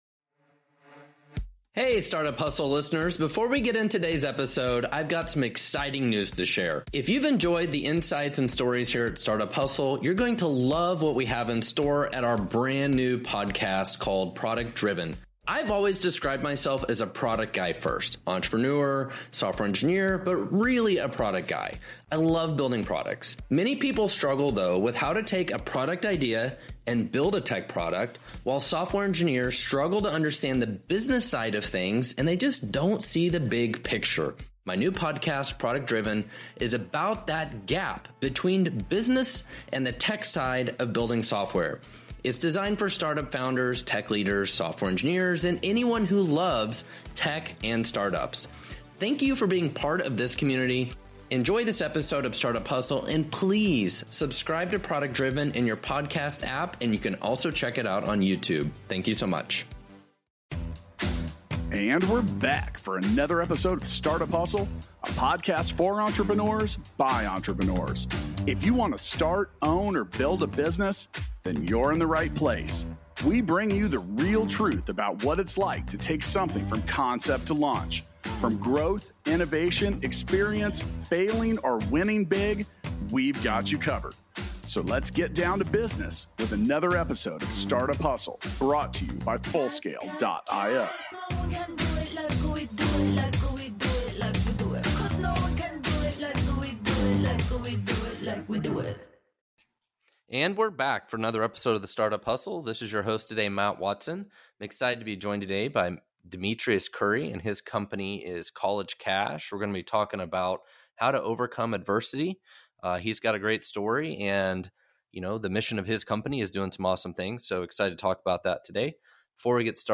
for a great conversation about overcoming adversity. Hear how various struggles and adversities are working to shape emotionally and mentally strong entrepreneurs for today and our future.